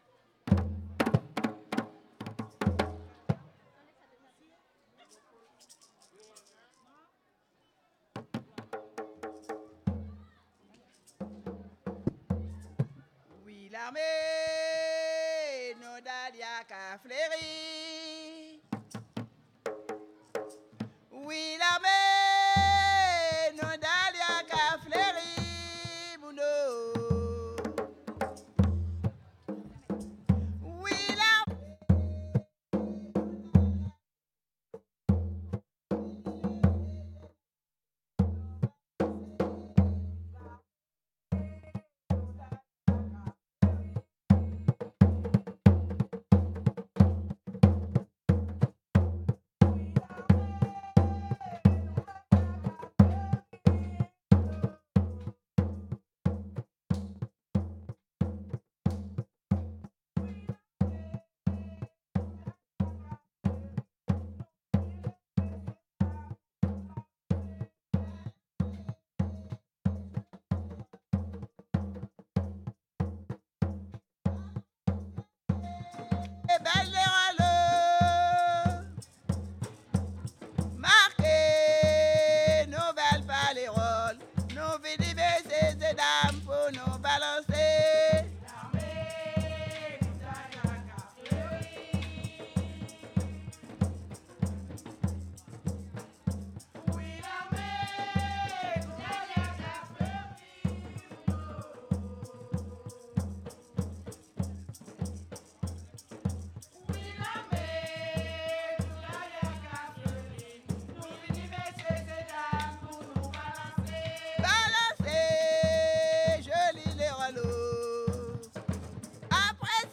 Entrée du groupe sur scène
danse : léròl (créole)
Pièce musicale inédite